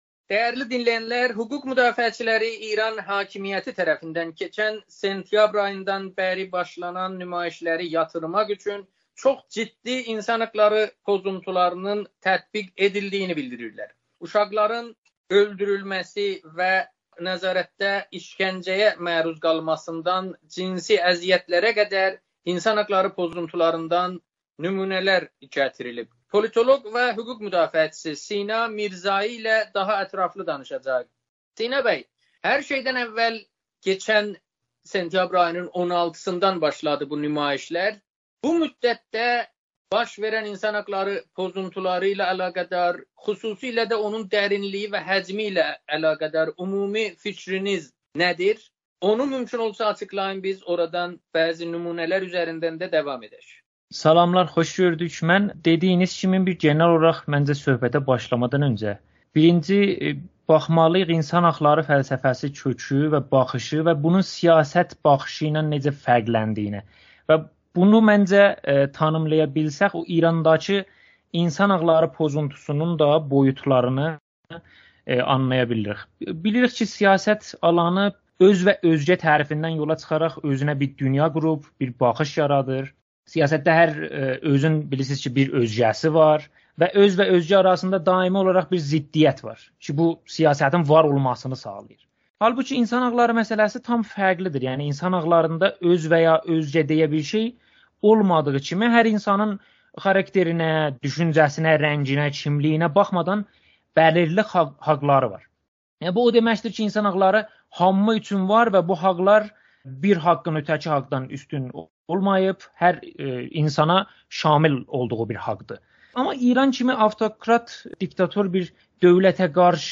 Amerikanın Səsi ilə söhbətdə